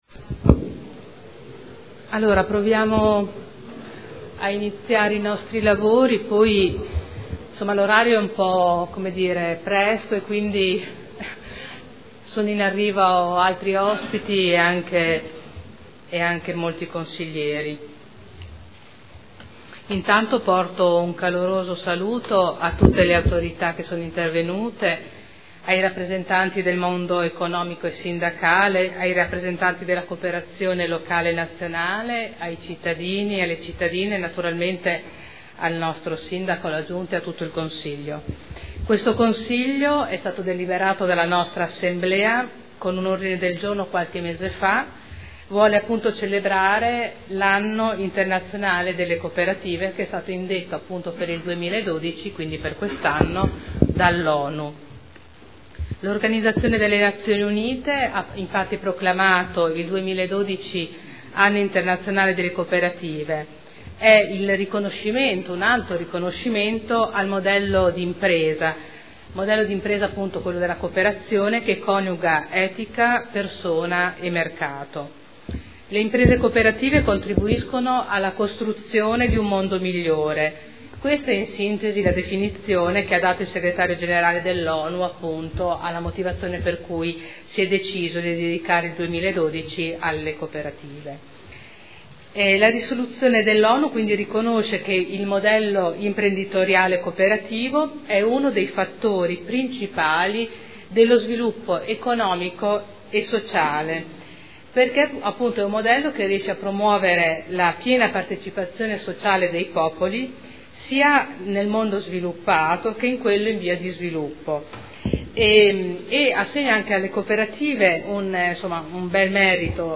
Seduta del 22/11/2012. Apertura lavori. Seduta interamente dedicata alla celebrazione dell’Anno internazionale delle cooperative indetto dall’ONU per il 2012